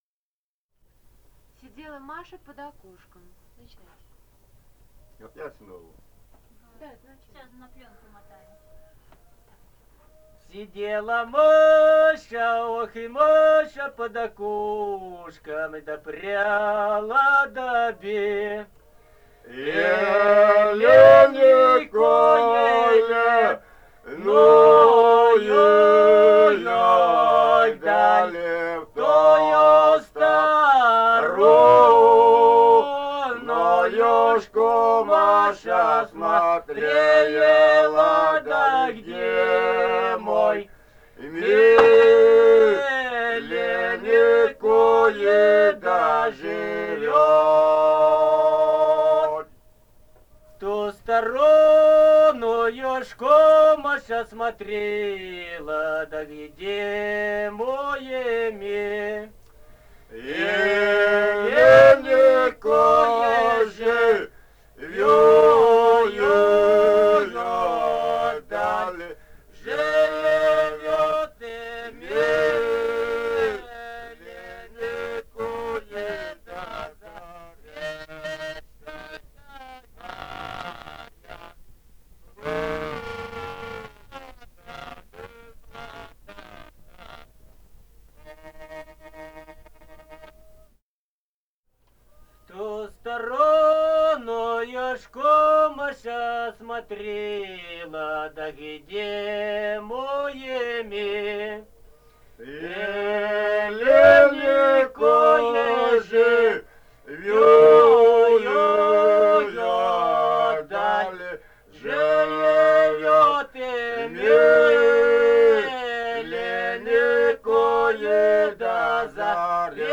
Этномузыкологические исследования и полевые материалы
«Сидела Маша под окошком» (лирическая).
Алтайский край, с. Маралиха Чарышского района, 1967 г. И1002-03